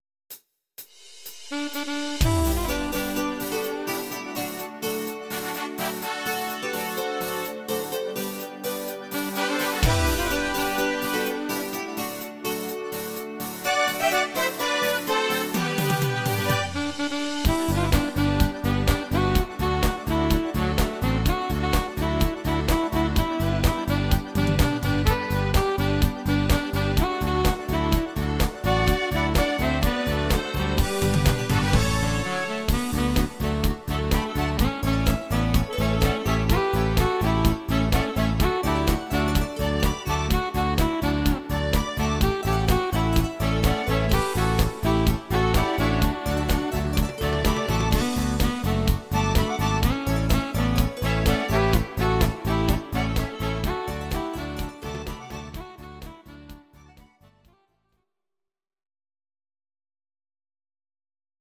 Discofox on Sax